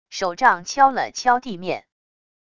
手杖敲了敲地面wav音频